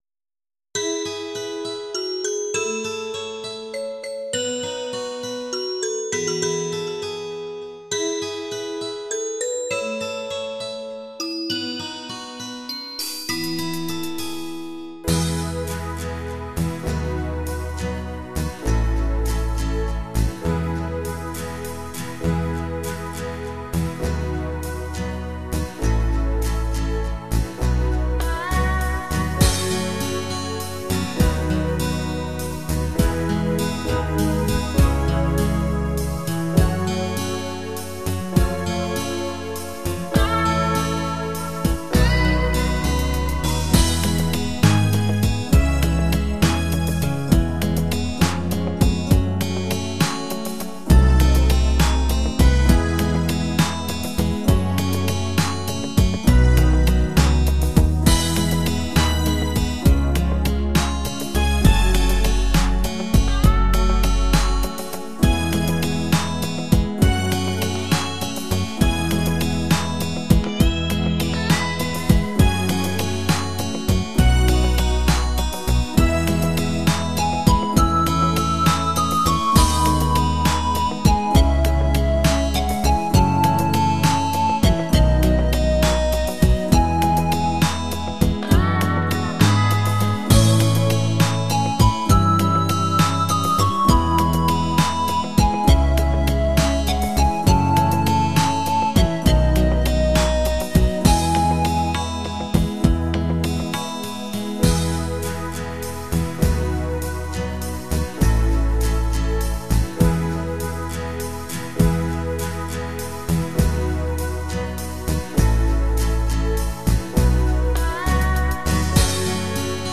Instrumental, do wspólnego